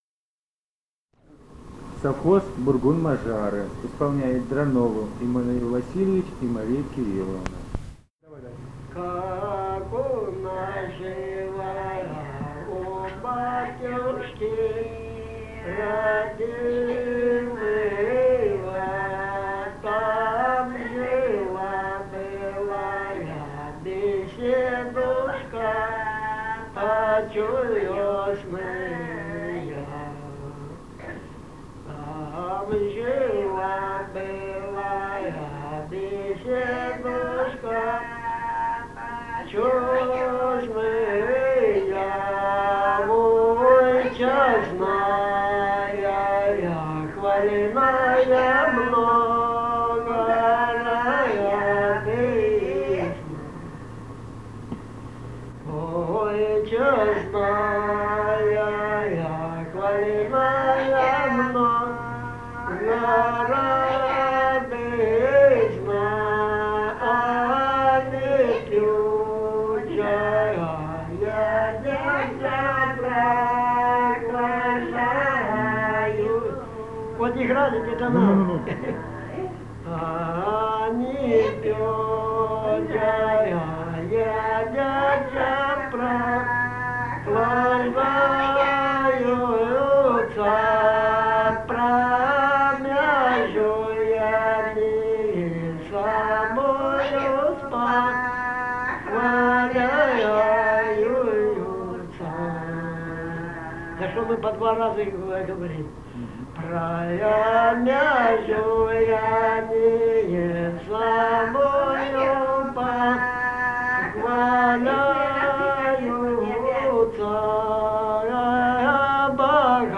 «Как у нашего у батюшки» (былевая).
Ставропольский край, с. Бургун-Маджары Левокумского района, 1963 г. И0718-04